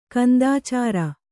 ♪ kandācāra